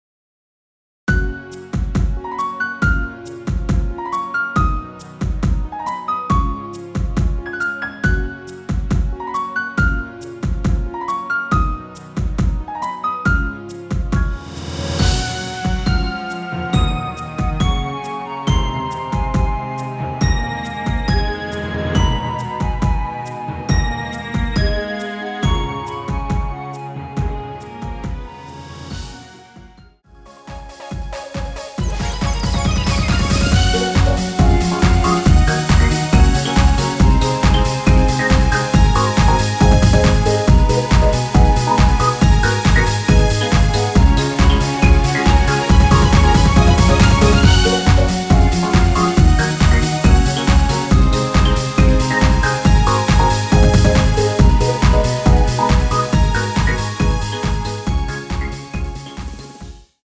원키에서(-1)내린 멜로디 포함된 MR입니다.(미리듣기 확인)
F#
앞부분30초, 뒷부분30초씩 편집해서 올려 드리고 있습니다.
중간에 음이 끈어지고 다시 나오는 이유는